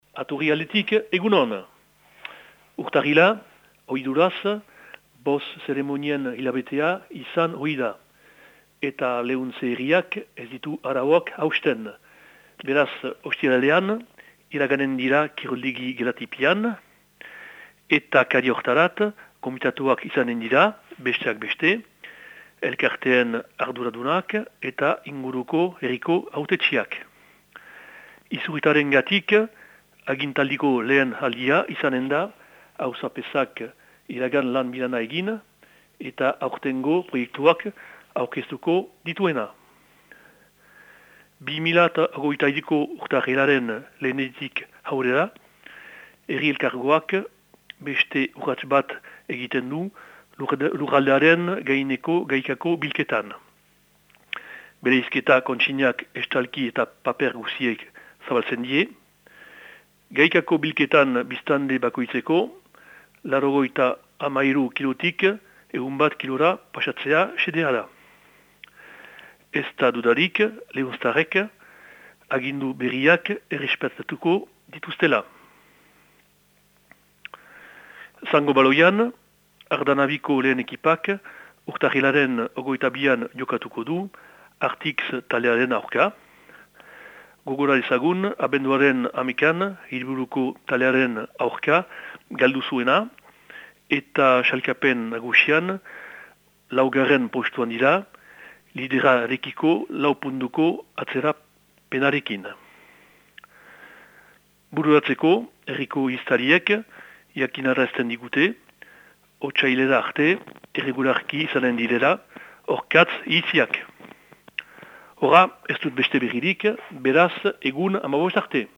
Urtarrilaren 12ko Lehuntzeko berriak